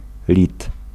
Ääntäminen
US
IPA : /ˈlɪθiəm/